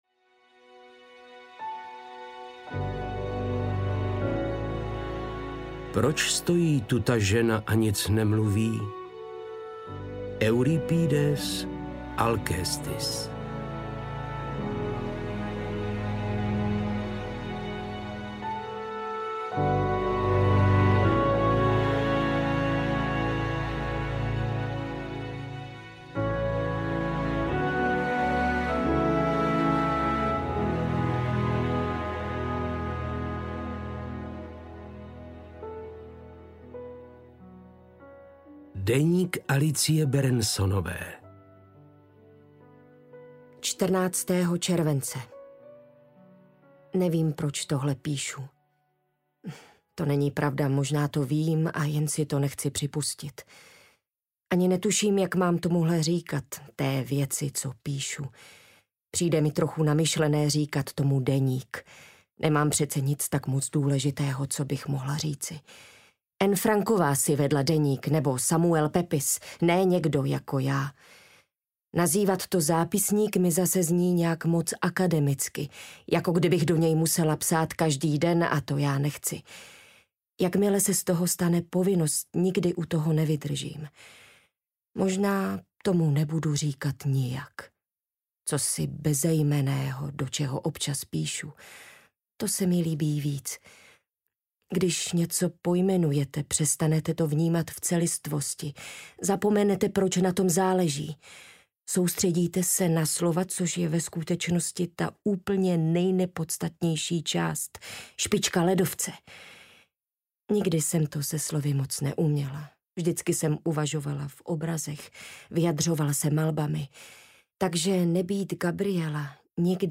Mlčící pacientka audiokniha
Ukázka z knihy
• InterpretLucie Štěpánková, Petr Stach